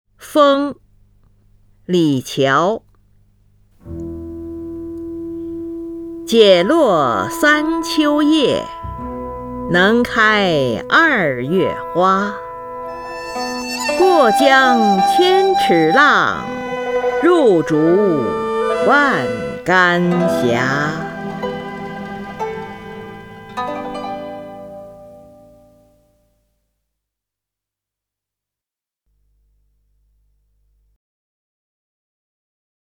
林如朗诵：《风》(（唐）李峤) （唐）李峤 名家朗诵欣赏林如 语文PLUS
（唐）李峤 文选 （唐）李峤： 林如朗诵：《风》(（唐）李峤) / 名家朗诵欣赏 林如